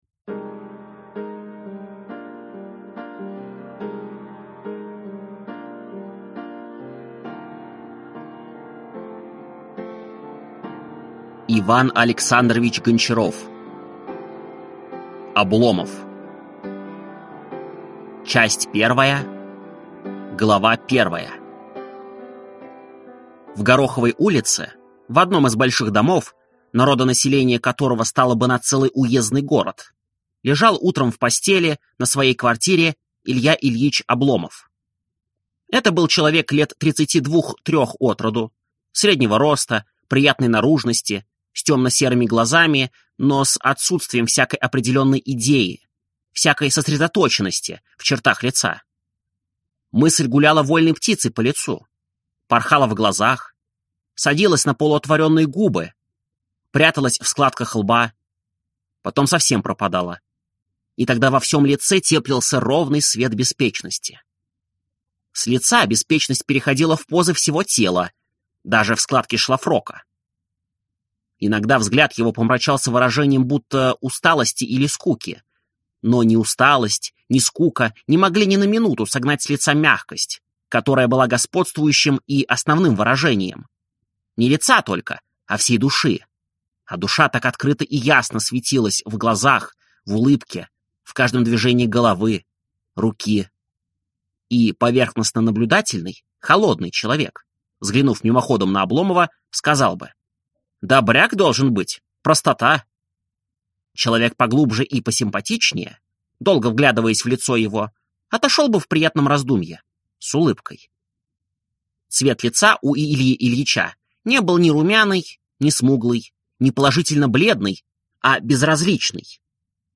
Аудиокнига Обломов | Библиотека аудиокниг